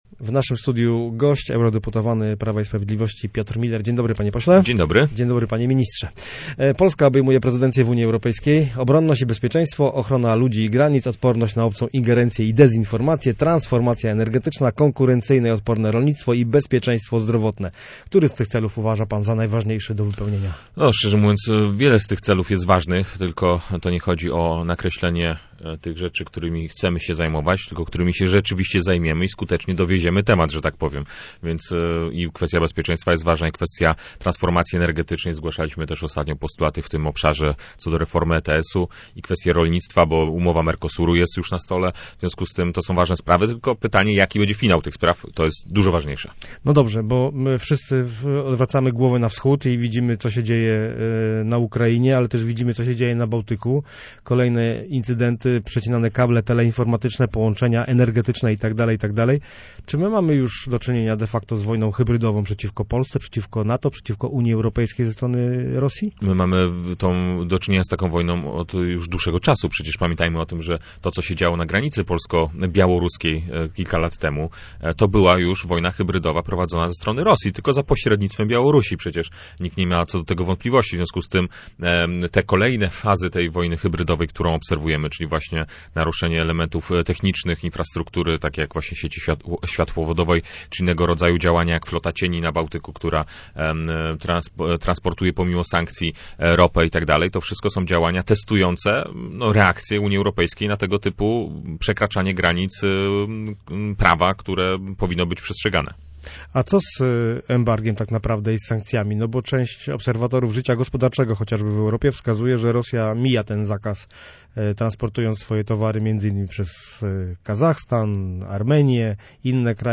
Posłuchaj rozmowy z Piotrem Müllerem